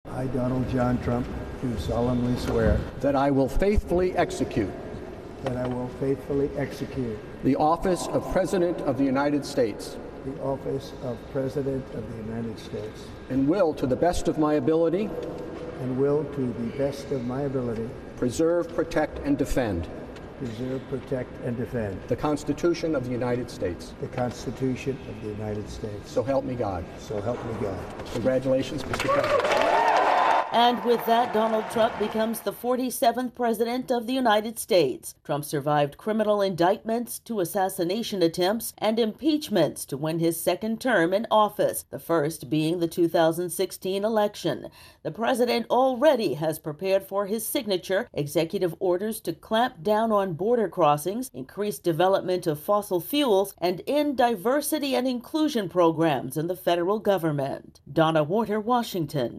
reports on Donald Trump's swearing in as the 47th President of the United States.